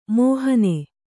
♪ mōhane